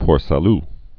(pôr să-l, -lü) also Port du Sa·lut (də)